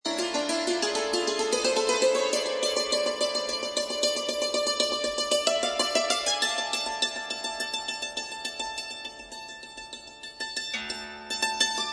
Audio clip of a Santur
Santur is kind of dulcimer with a flat trapeze body. It has from 16 to 24 strings in pairs, or triplets, tuned chromatically. The strings are struck with a light wooden sticks whose ends are slightly curved upwards (pictured in middle of santur).
santur.mp3